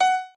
admin-fishpot/b_piano1_v100l8o6fp.ogg at main